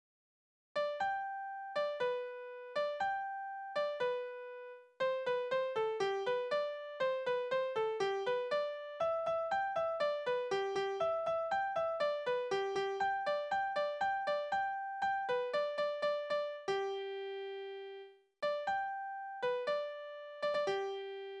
Lieder zu festlichen Gelegenheiten: Hochzeitslied
Tonart: G-Dur
Taktart: 2/4
Tonumfang: Oktave
Besetzung: vokal